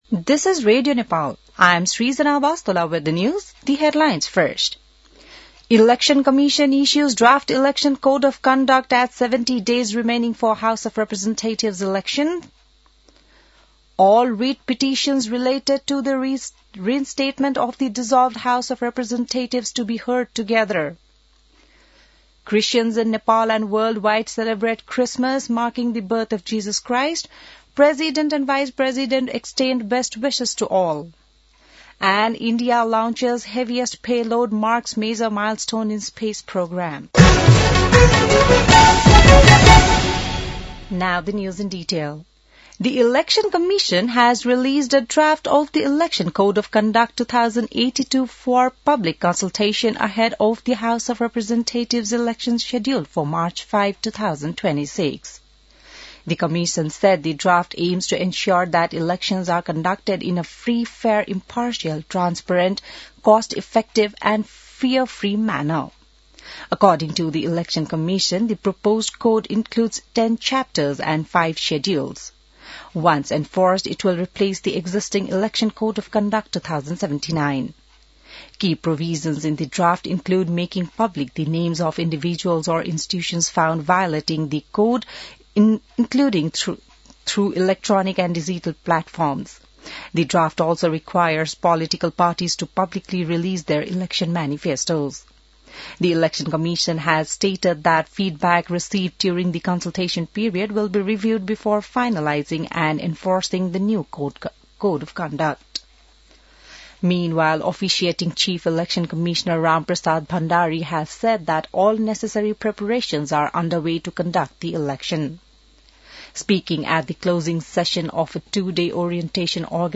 An online outlet of Nepal's national radio broadcaster
बिहान ८ बजेको अङ्ग्रेजी समाचार : १० पुष , २०८२